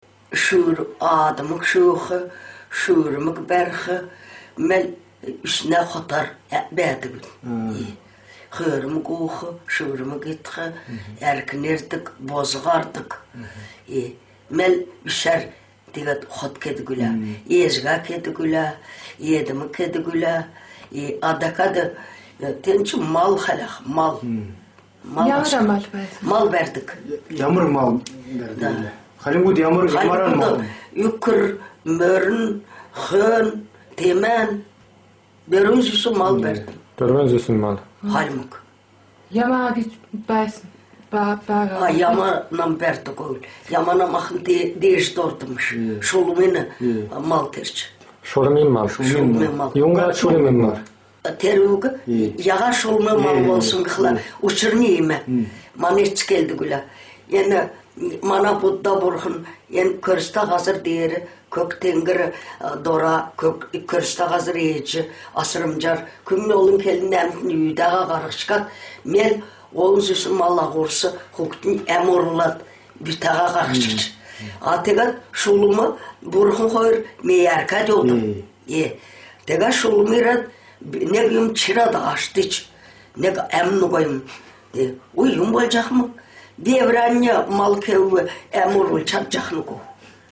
The younger voices sound very standard modern Mongolian to me, but I was thrown off by the elderly woman’s accent, which doesn’t sound quite as standard.